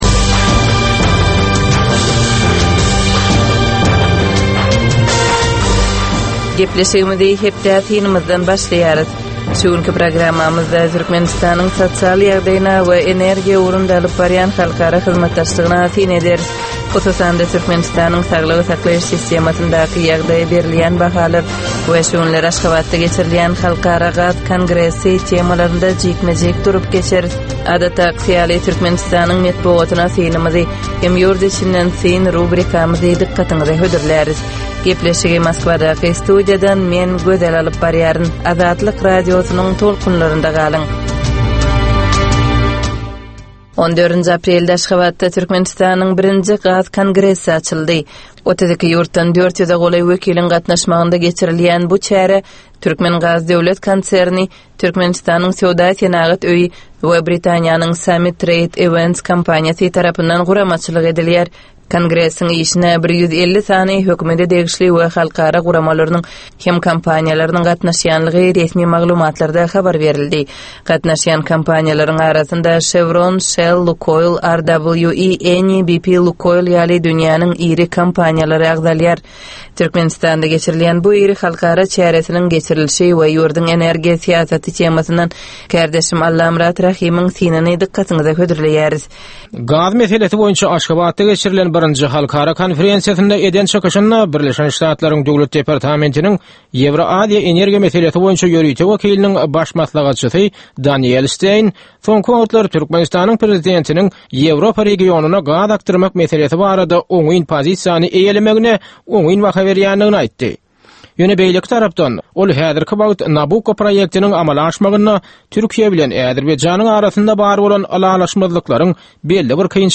Tutuş geçen bir hepdäniň dowamynda Türkmenistanda we halkara arenasynda bolup geçen möhüm wakalara syn. 25 minutlyk bu ýörite programmanyň dowamynda hepdäniň möhüm wakalary barada gysga synlar, analizler, makalalar, reportažlar, söhbetdeşlikler we kommentariýalar berilýär.